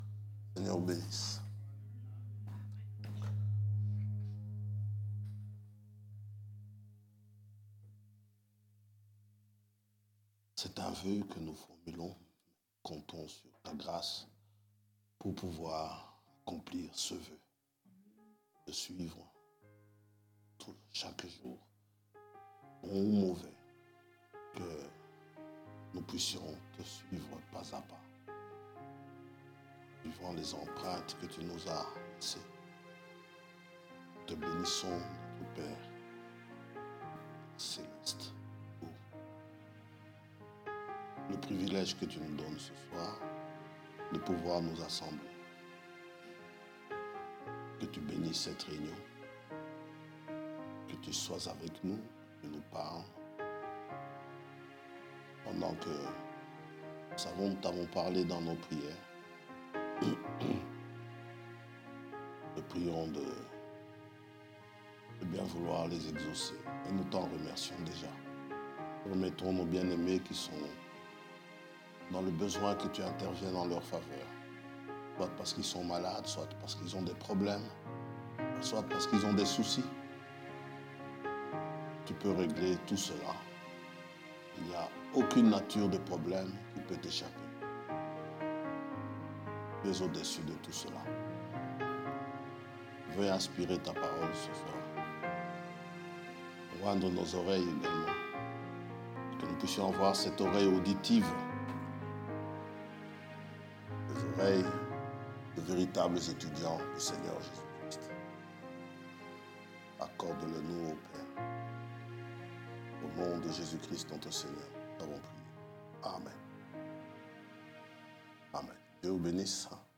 Catégorie: Prédications